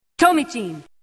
Announcer: "